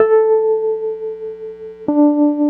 Track 14 - Wurlitzer 02.wav